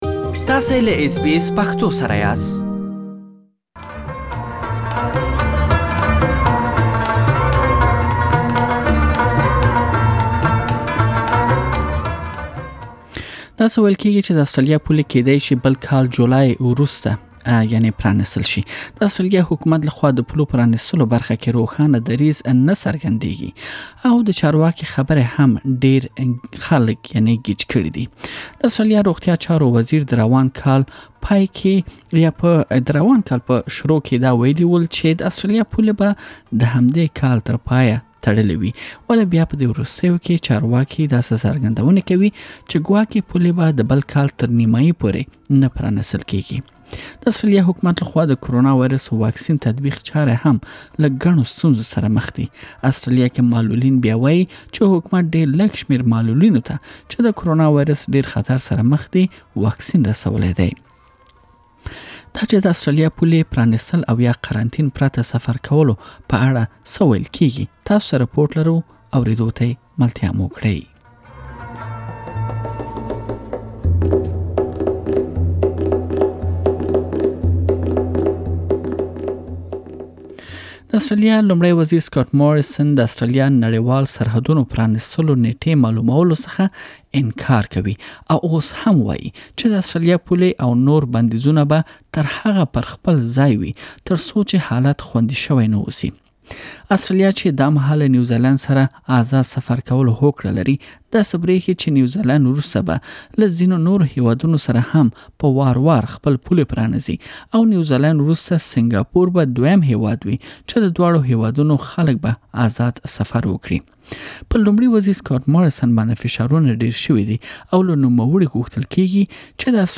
تاسو ته مو يادې موضوع په اړه رپوټ چمتو کړی چې دلته يې اوريدلی شئ.